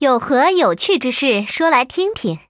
文件 文件历史 文件用途 全域文件用途 Erze_tk_03.ogg （Ogg Vorbis声音文件，长度0.0秒，0 bps，文件大小：32 KB） 源地址:游戏语音 文件历史 点击某个日期/时间查看对应时刻的文件。